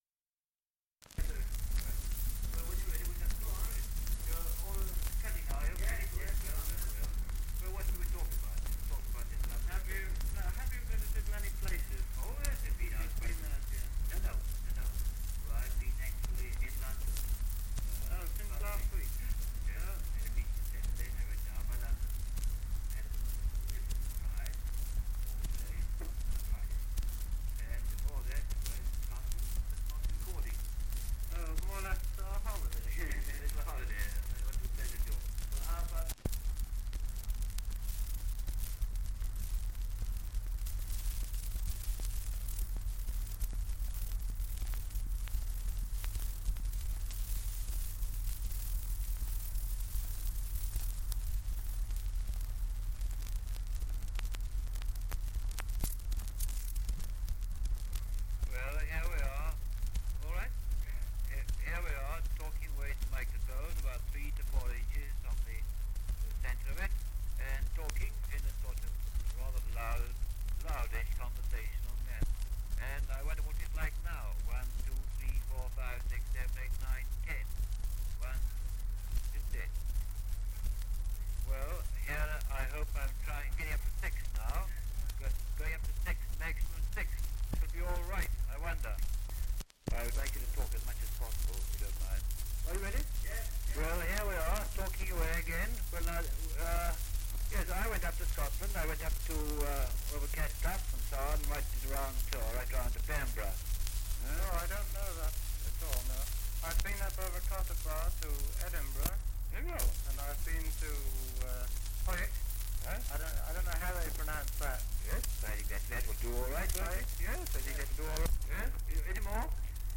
Dialect recording in Wall, Northumberland
78 r.p.m., cellulose nitrate on aluminium